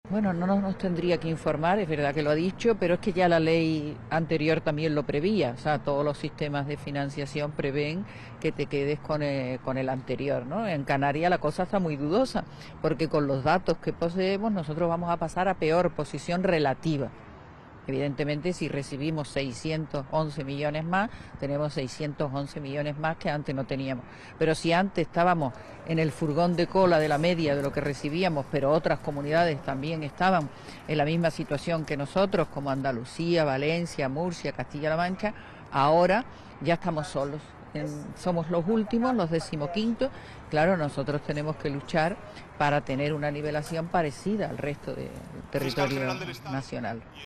Declaraciones de la consejera de Hacienda y Relaciones con la Unión Europea, Matilde Asián: